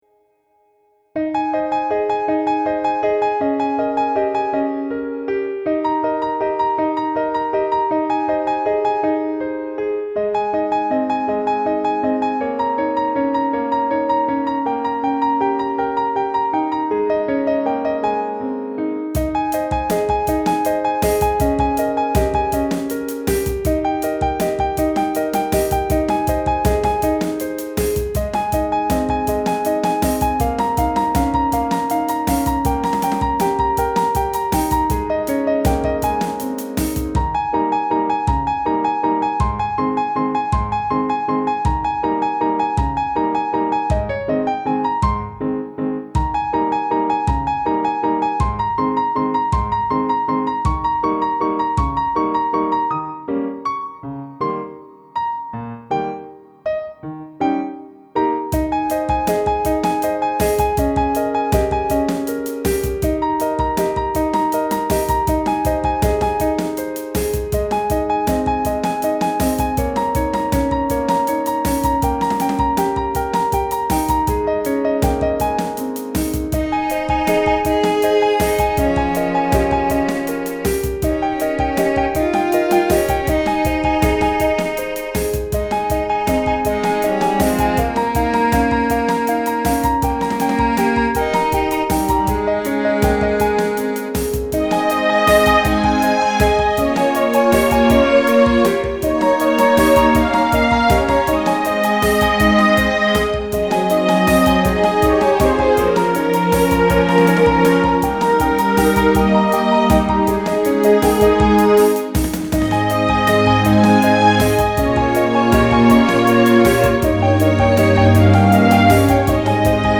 ディズニー風に作りました。　　さすがに最近のディズニーは見ていないが昔のディズニーのイメージで。
曲はお姫様登場という感じです。
そして最後のオーケストラ音色はローランドです。　もう３０〜４０年近く古い音源。